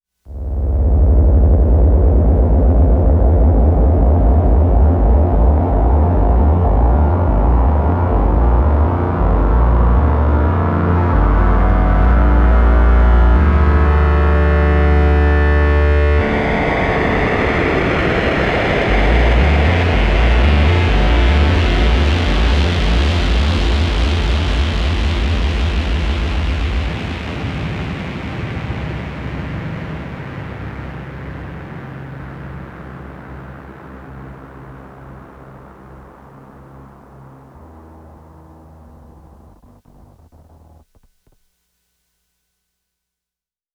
Like a massive bee swarm.
I’ve used 2 LFOs. One Ramp LFO on the speed (my classic that I wanted to share so thanks for asking :rofl:) and one Saw LFO on the Filter Frequency.
I’ve noticed that it sounds awful on a cell phone because a lot of the interesting things are in the Low registry.